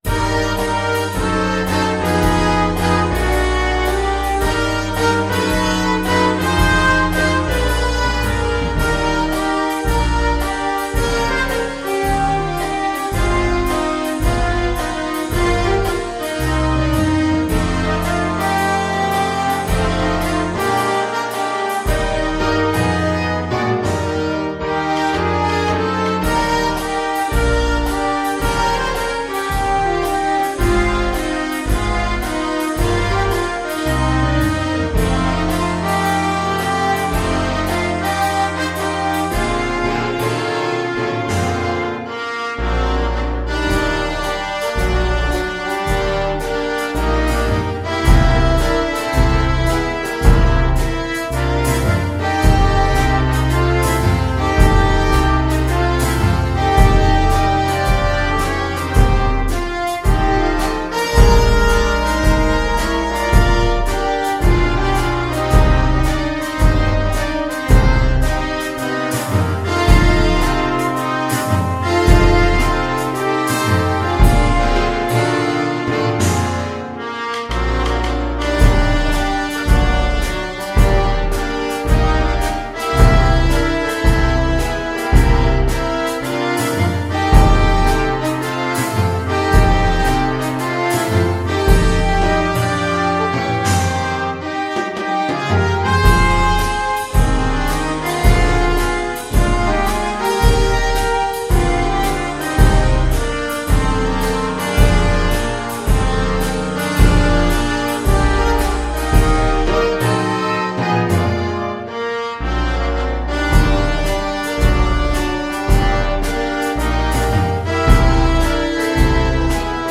Big Band version
2/2 (View more 2/2 Music)
~ = 110 Moderate swing